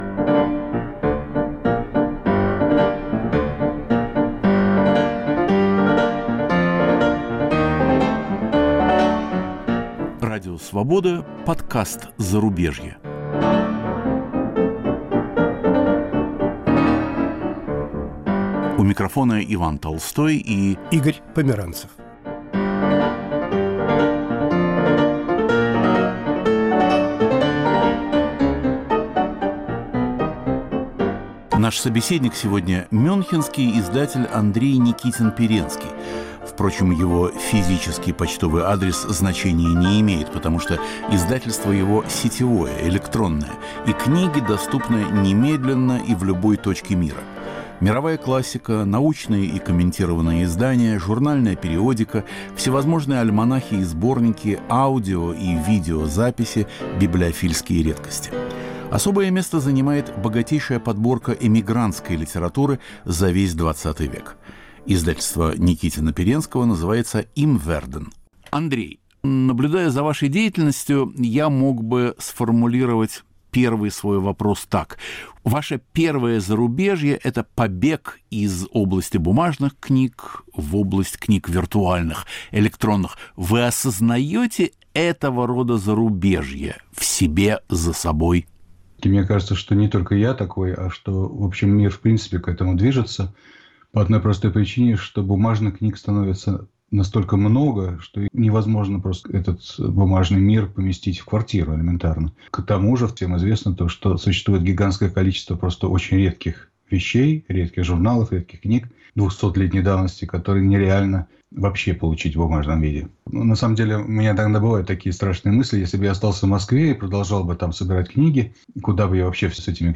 Радио Свобода продолжает цикл подкастов "Зарубежье". В сегодняшнем выпуске – беседа